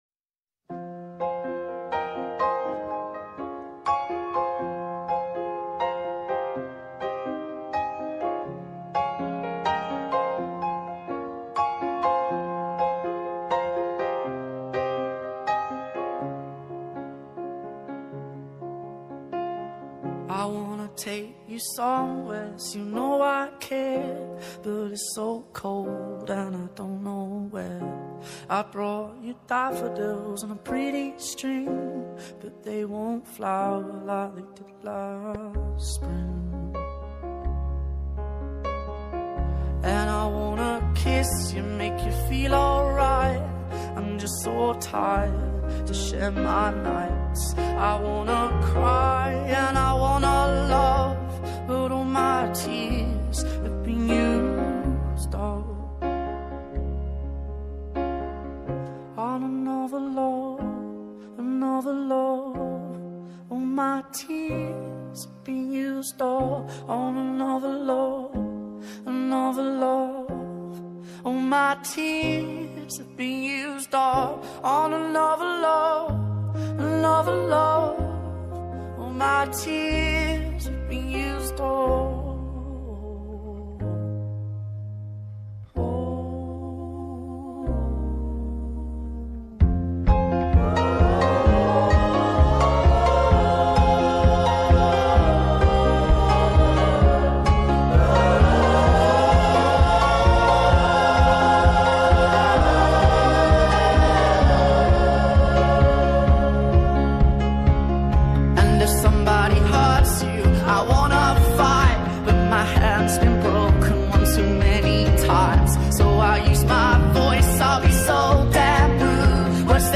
آهنگ های خارجی
غمگین